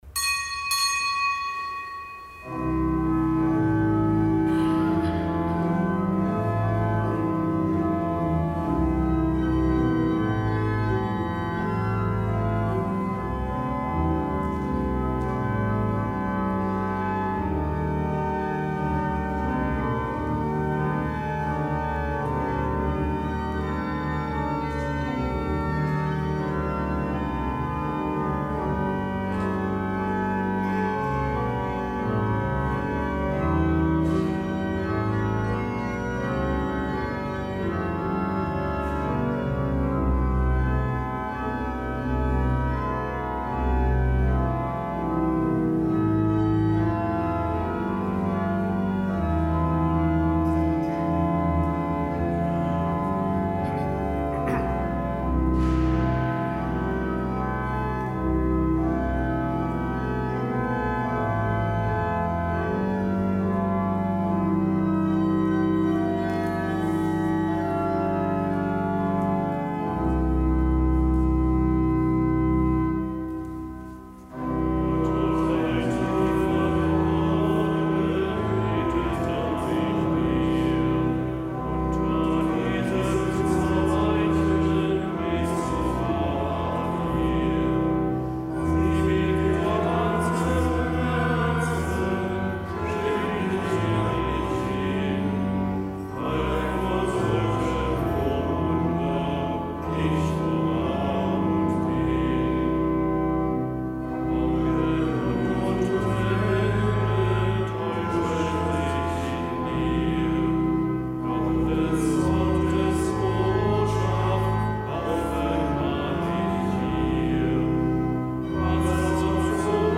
Kapitelsmesse aus dem Kölner Dom am Gedenktag Heiliger Thomas von Aquin, Ordenspriester, Kirchenlehrer; Zelebrant: Weihbischof Ansgar Puff.